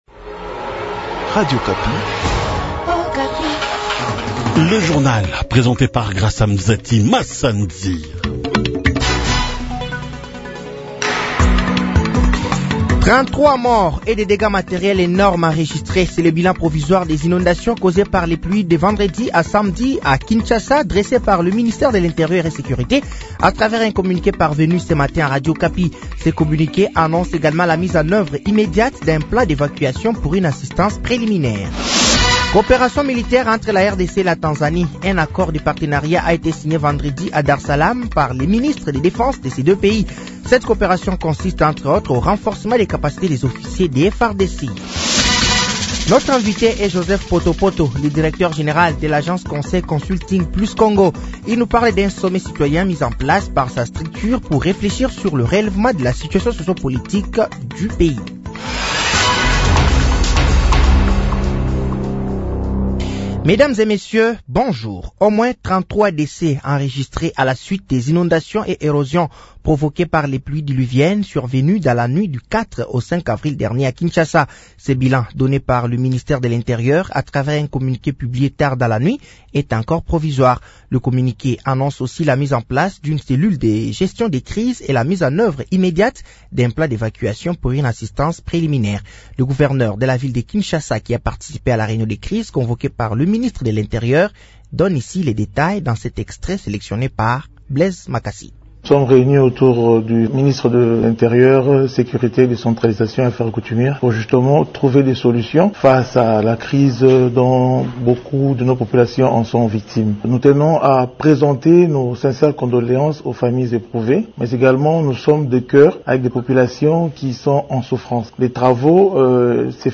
Journal français de 08h de ce lundi 07 avril 2025